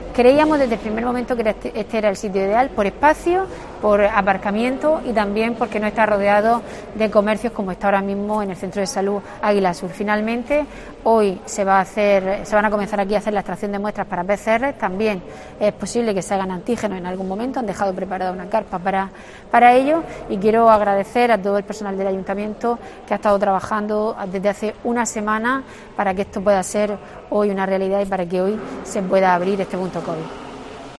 María del Carmen Moreno, alcaldesa de Águilas, sobre punto COVID